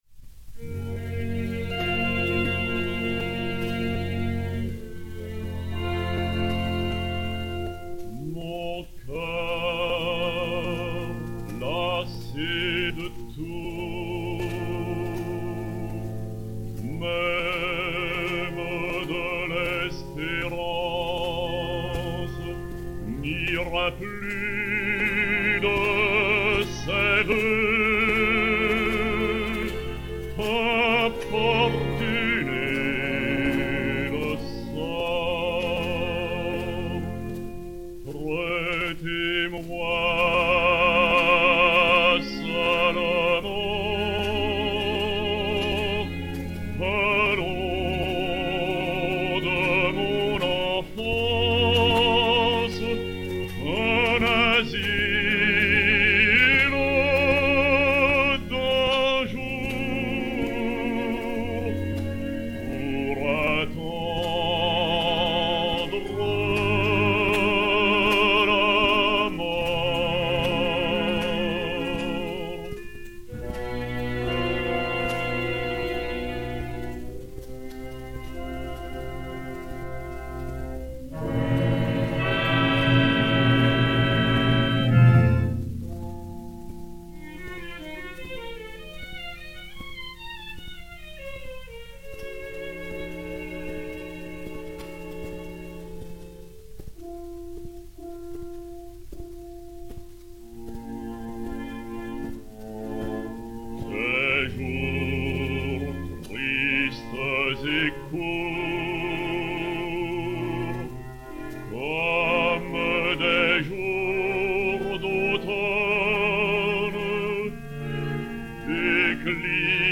Orchestre